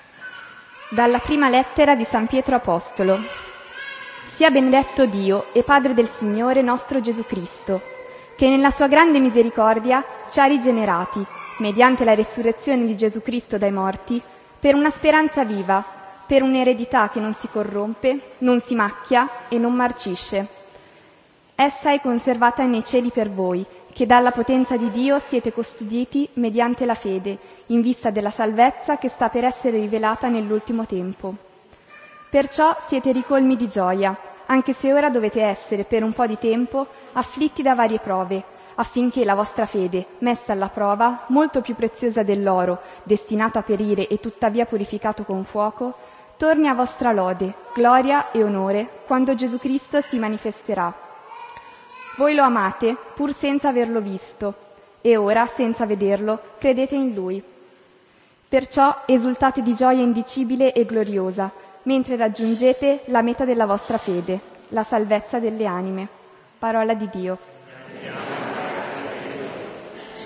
Raduno Giovani 2011 S.Messa – audio
08-Seconda_lettura.ogg